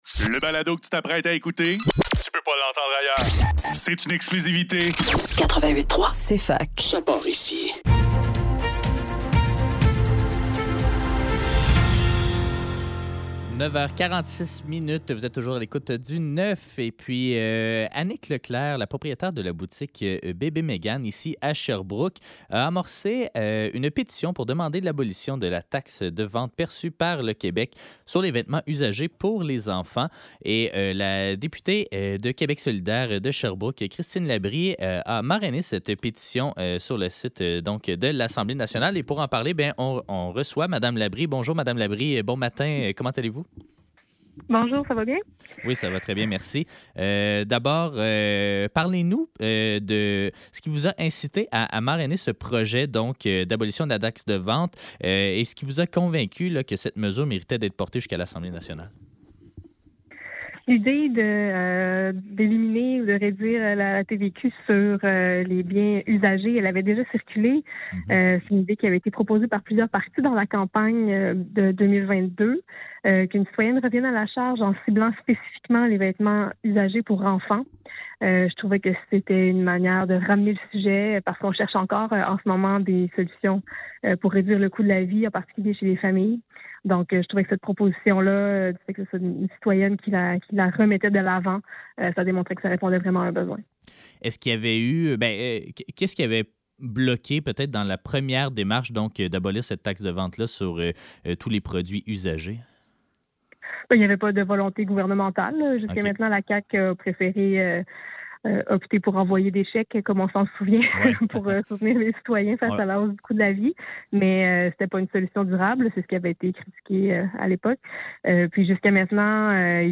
Le neuf - Entrevue avec Christine Labrie concernant la pétition sur l'abolition de la taxe de vente du Québec sur les vêtements usagés pour les enfants - 17 novembre 2025